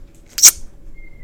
infinitefusion-e18/Audio/SE/Cries/LUVDISC.mp3 at releases-April